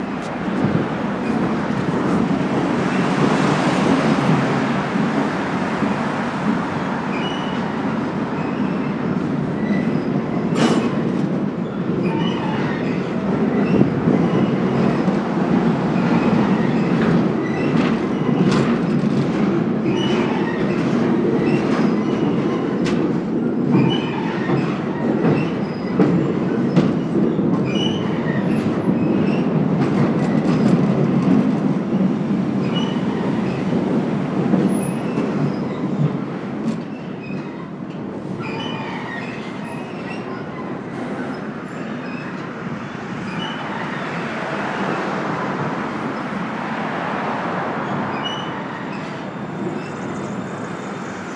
Underneath viaduct as freight passes over